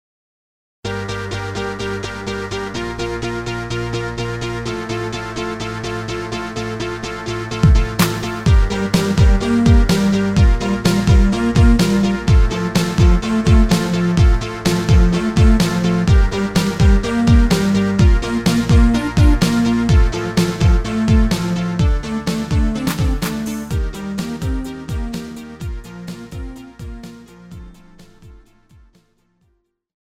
Žánr: Pop
MP3 ukázka